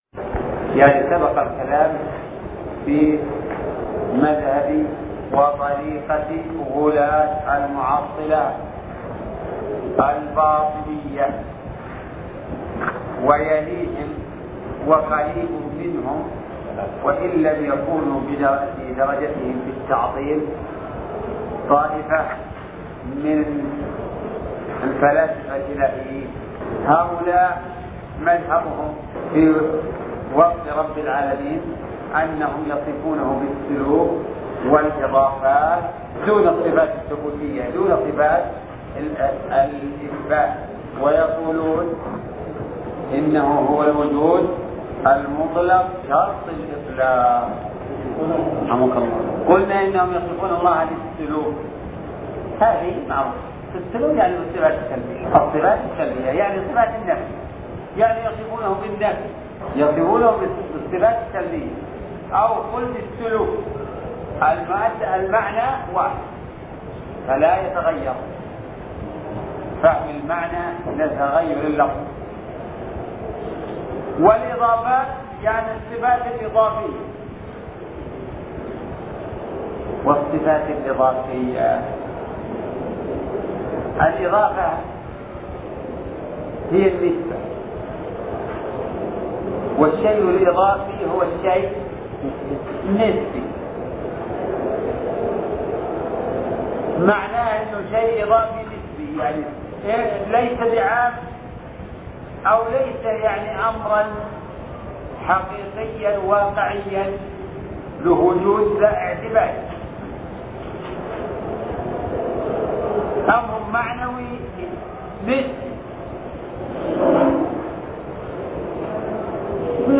عنوان المادة شرح الرسالة التدمرية (6) الدرس السادس تاريخ التحميل السبت 19 فبراير 2022 مـ حجم المادة 25.25 ميجا بايت عدد الزيارات 147 زيارة عدد مرات الحفظ 66 مرة إستماع المادة حفظ المادة اضف تعليقك أرسل لصديق